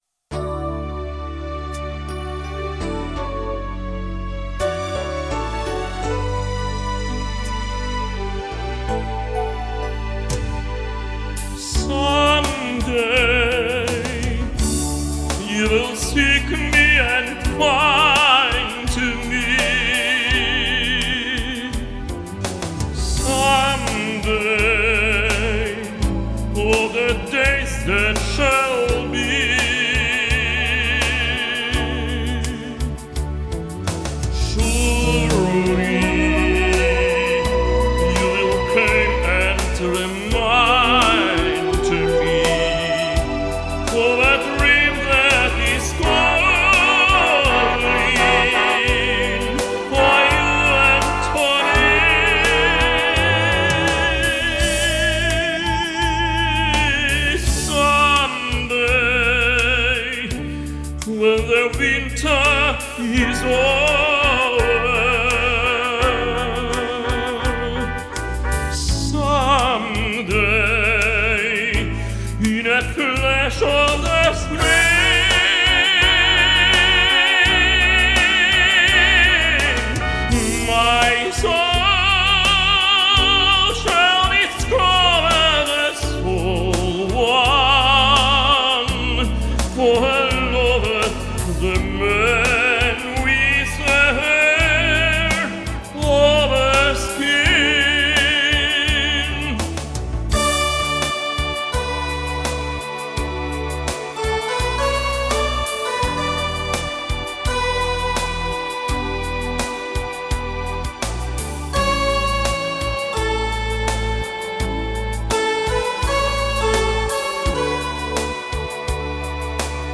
Tenor.